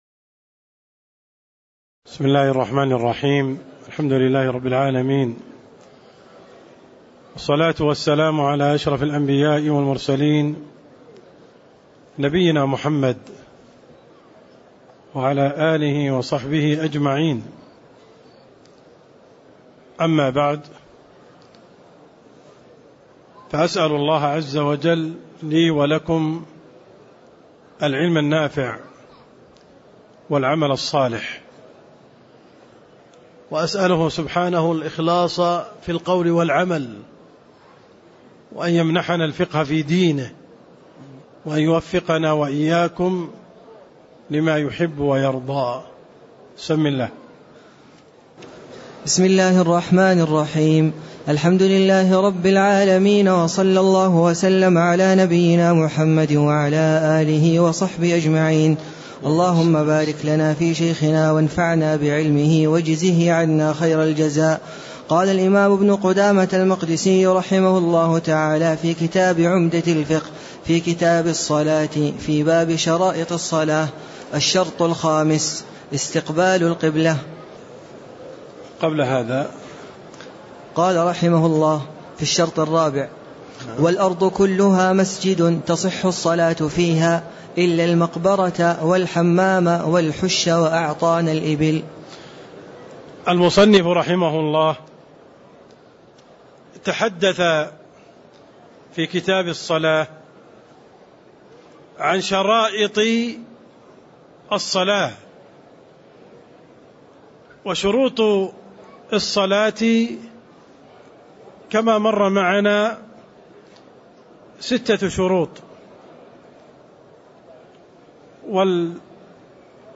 تاريخ النشر ١١ محرم ١٤٣٦ هـ المكان: المسجد النبوي الشيخ: عبدالرحمن السند عبدالرحمن السند باب شرائط الصلاة (06)